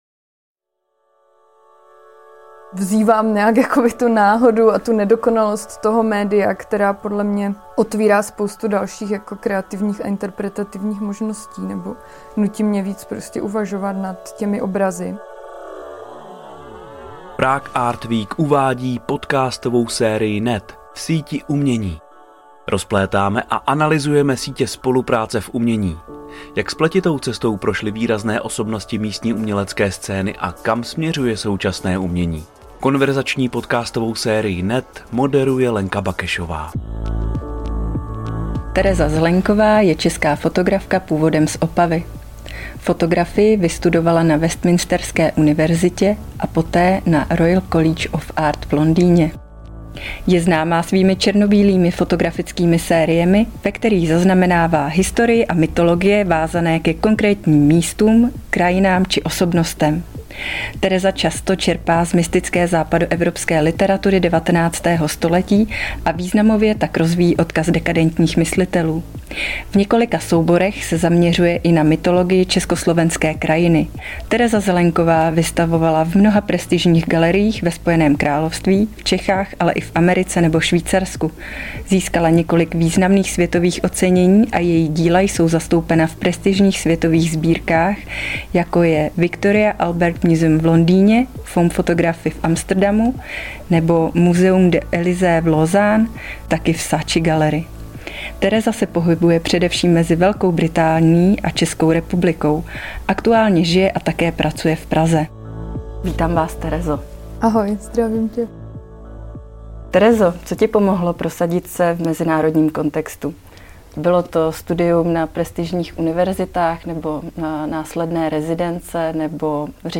V konverzační podcastové sérii NET rozplétáme a analyzujeme sítě spolupráce v umění.